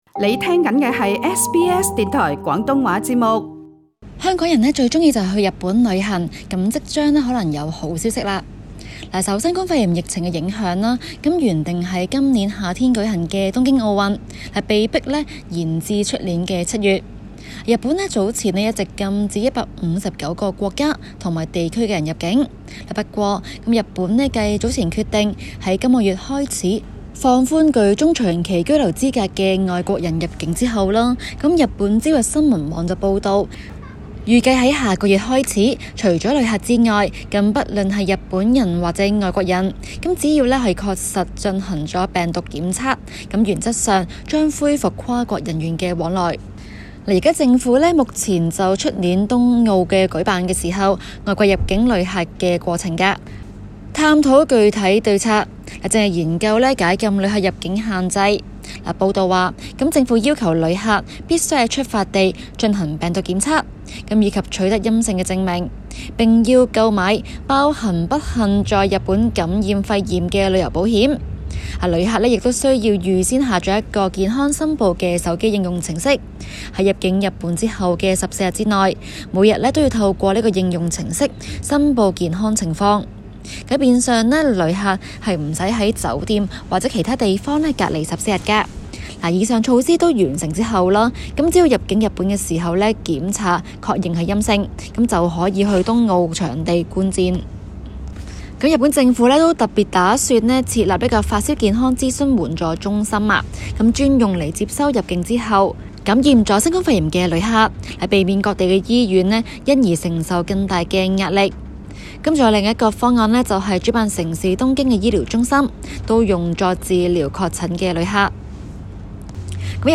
《中港快讯》中的报导